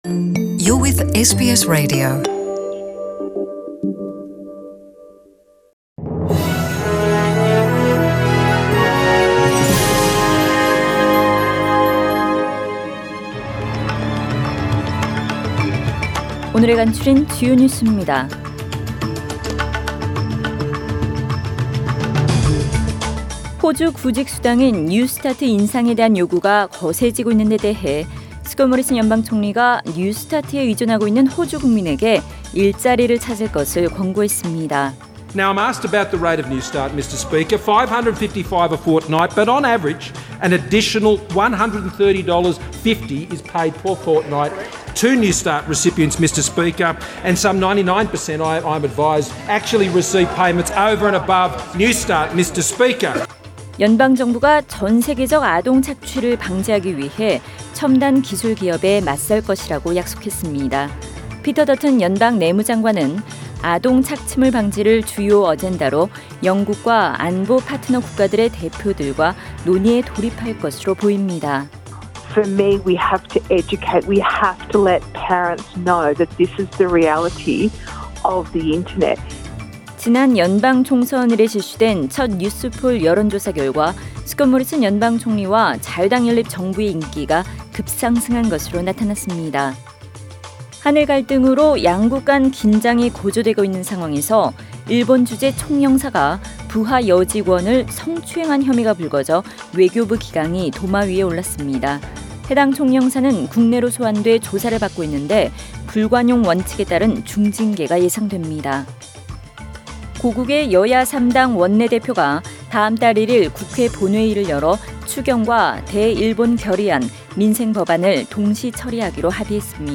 SBS 한국어 뉴스 간추린 주요 소식 – 7월 29일 월요일
2019년 7월 29일 월요일 저녁의 SBS Radio 한국어 뉴스 간추린 주요 소식을 팟 캐스트를 통해 접하시기 바랍니다.